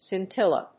scin・til・la /sɪntílə/
• / sɪntílə(米国英語)